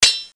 1 channel
Sword3.mp3